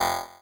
Error3.wav